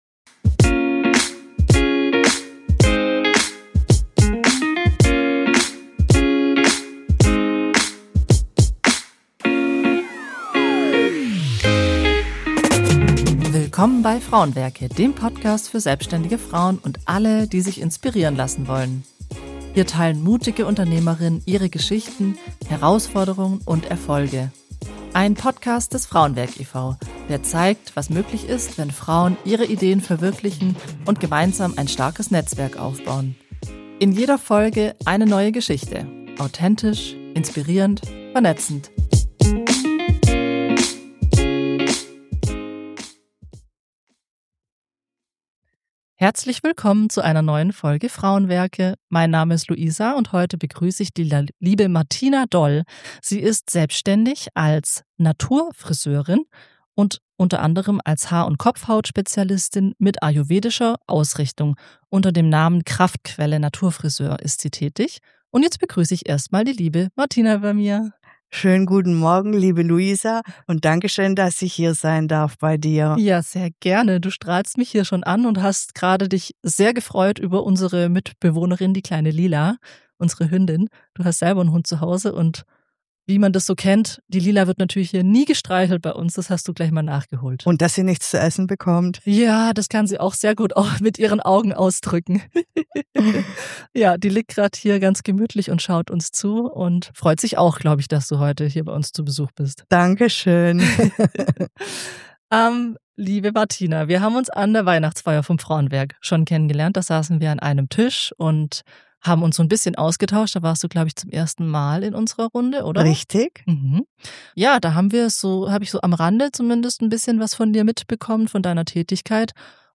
Ein tiefgründiges, warmherziges Gespräch über Entschleunigung, Naturverbundenheit und den Mut, einen eigenen Weg zu gehen – zurück zu sich selbst.